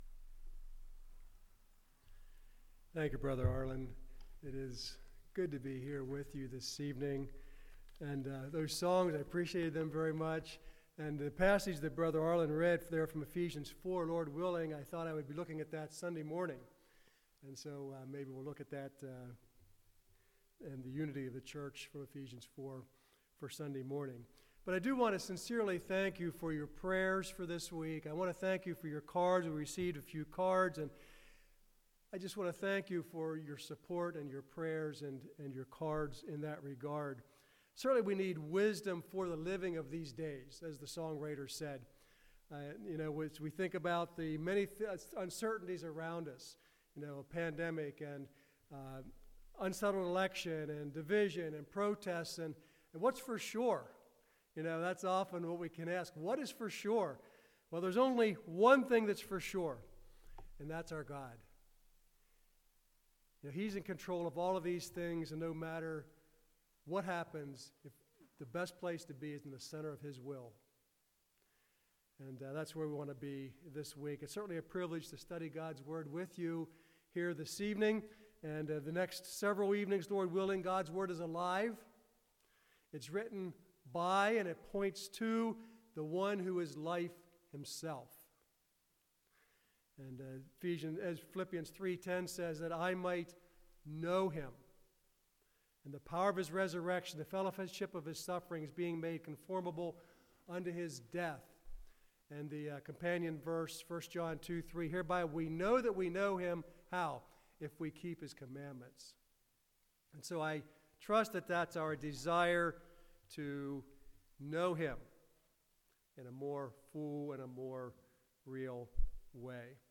Service Type: Revivals